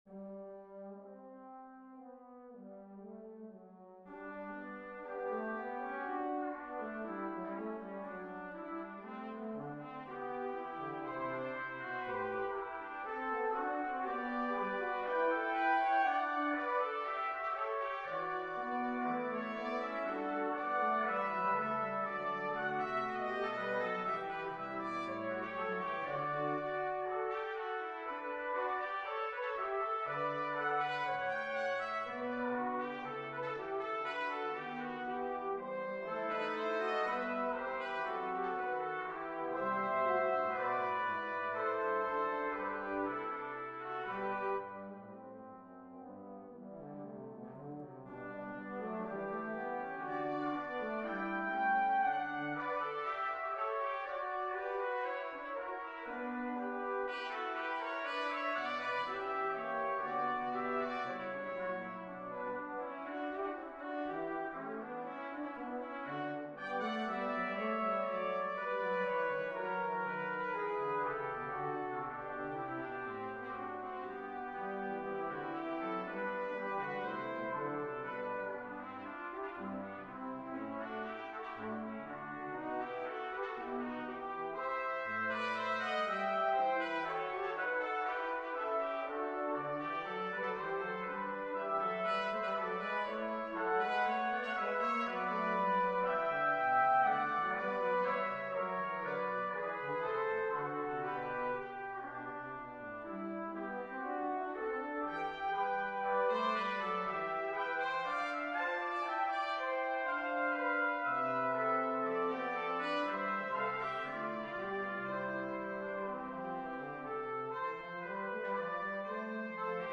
Brass Ensembles
2 Trumpets, 2 Trombones